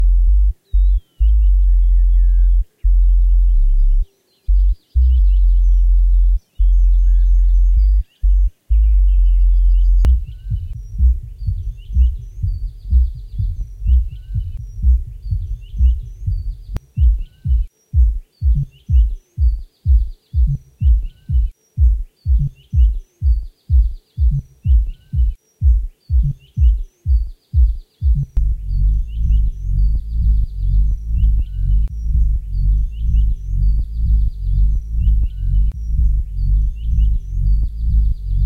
Пример сабов - лупы из семплов...Птички поют- это защитный тэг.